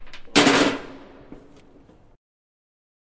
废弃的工厂金属后世界末日的回声" 砰 1
描述：记录在都柏林的一家废弃工厂。
Tag: 工业 FAC托里 金属 崩溃 噪声